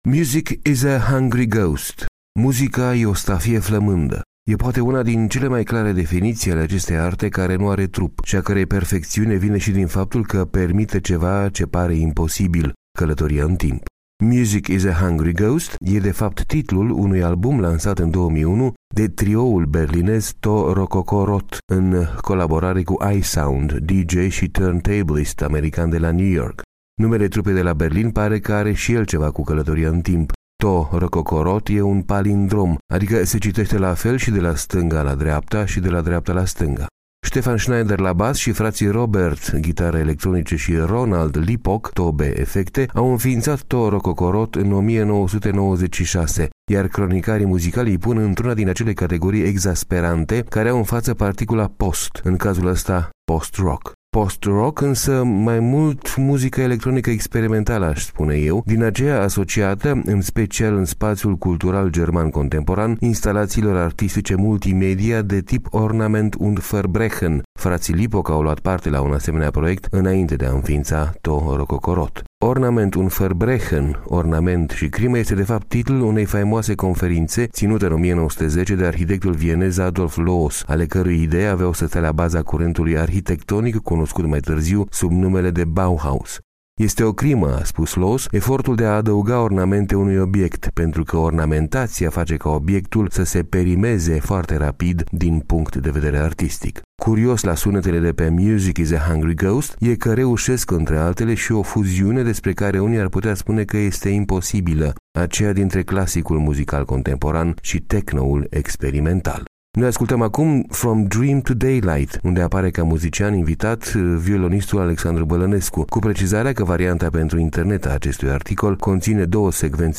O fuziune despre care unii ar putea spune că este imposibilă, aceea dintre clasicul muzical contemporan și techno-ul experimental.